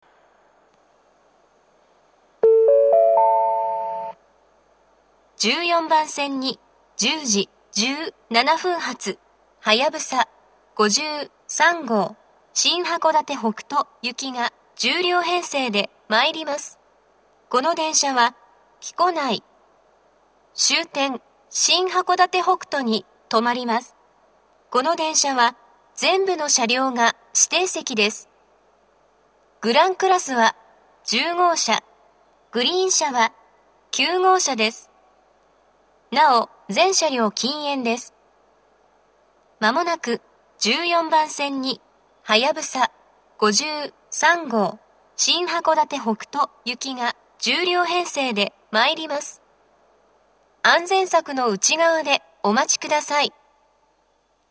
１４番線接近放送
接近放送及び到着放送は「はやぶさ５３号　新函館北斗行」です。